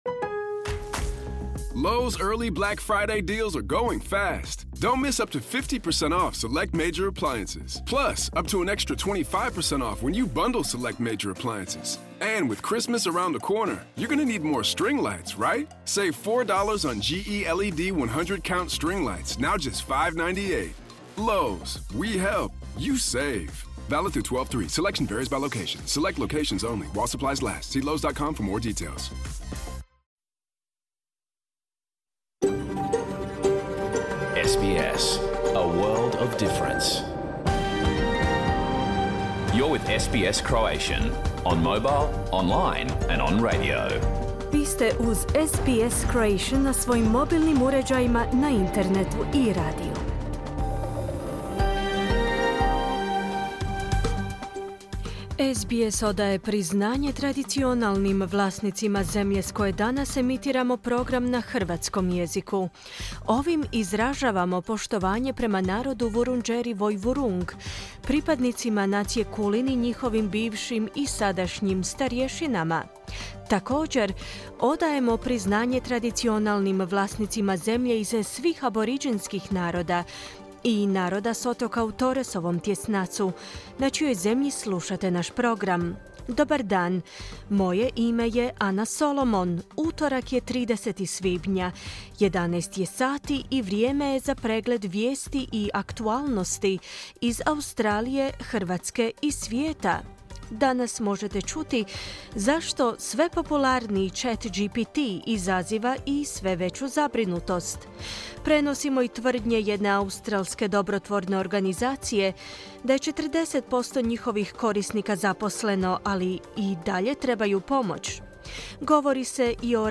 Pregled vijesti i aktualnih tema iz Australije, Hrvatske i ostatka svijeta. Program je emitiran uživo u utorak, 30.5.2023. u 11 sati.